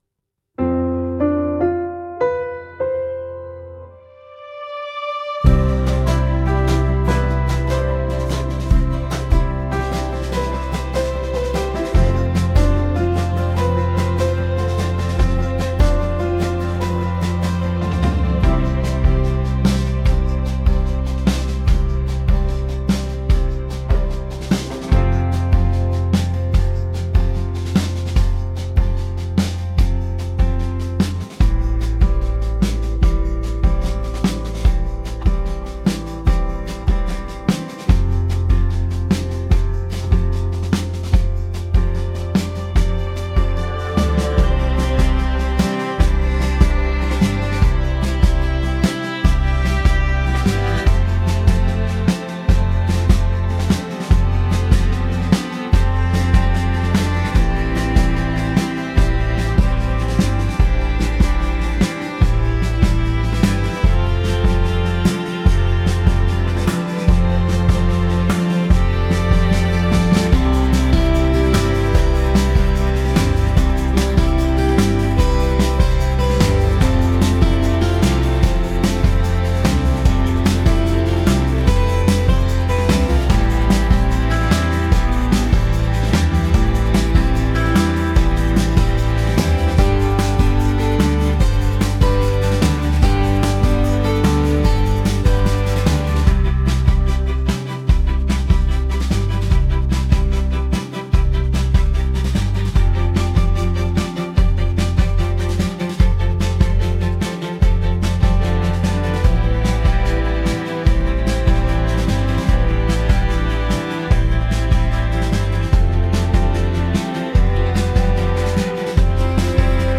Trilha Sonora